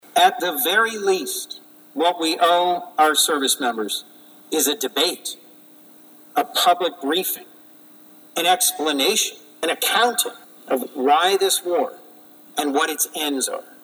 Senator Coons Speaks on Senate Floor Regarding War in Iran